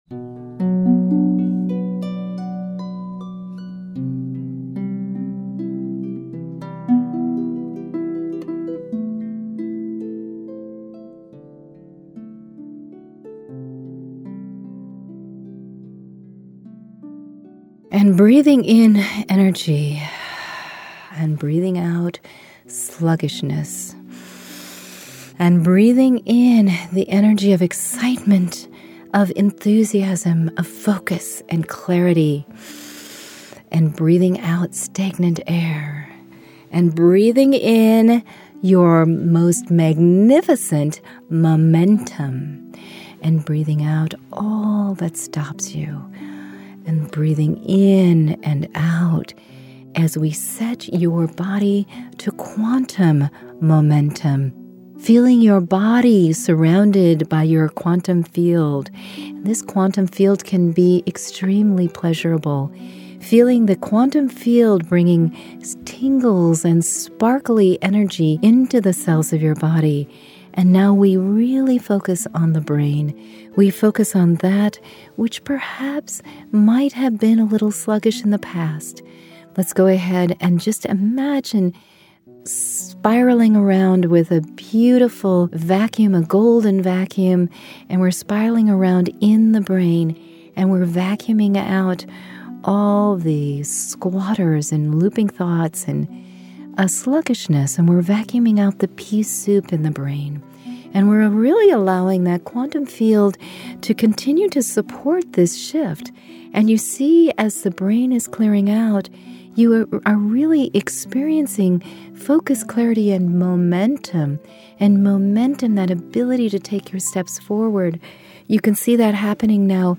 Q5 Meditations